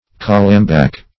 Search Result for " calambac" : The Collaborative International Dictionary of English v.0.48: Calambac \Cal"am*bac\ (k[a^]l"[a^]m*b[a^]k), n. [F. calambac, calambour, from Malay Kalambaq a king of fragrant wood.] (Bot.) A fragrant wood; agalloch.